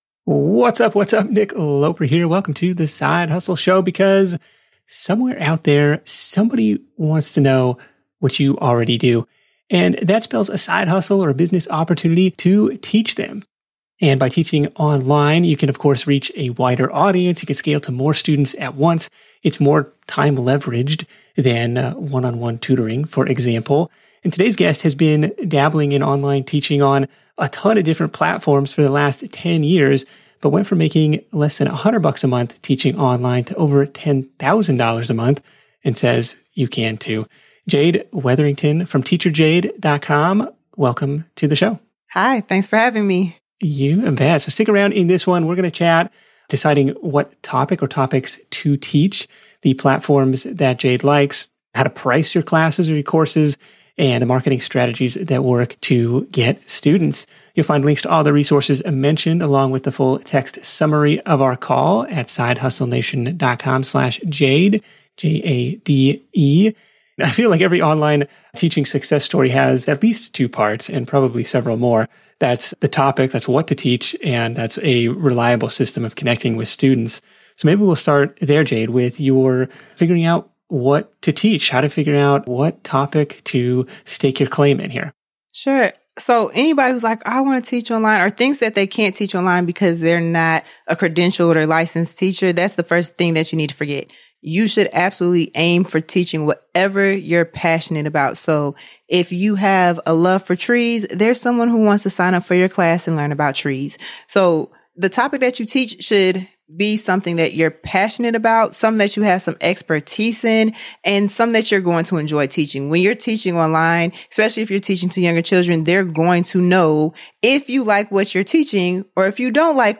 Tune in to The Side Hustle Show interview